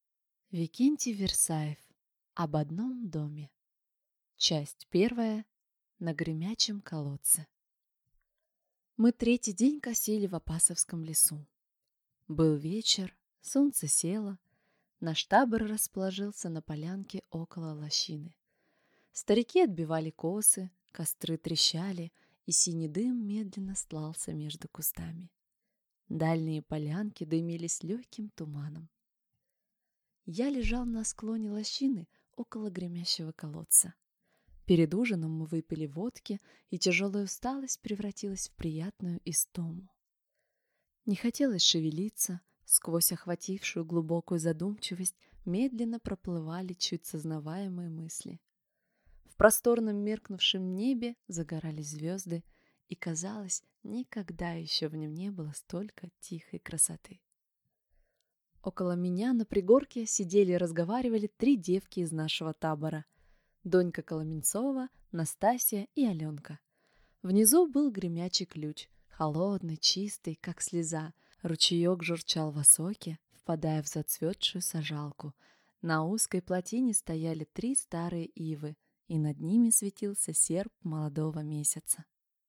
Аудиокнига Об одном доме | Библиотека аудиокниг